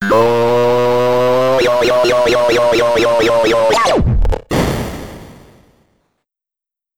CD-ROOM/Assets/Audio/SFX/BossDie.wav at main
BossDie.wav